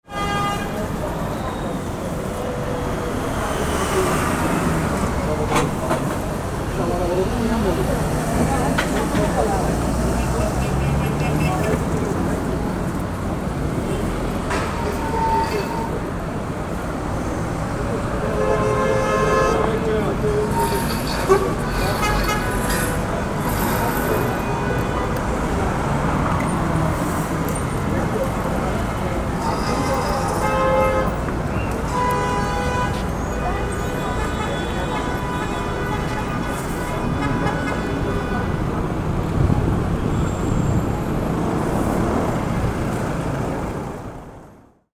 Authentic-istanbul-traffic-jam-sound-effect.mp3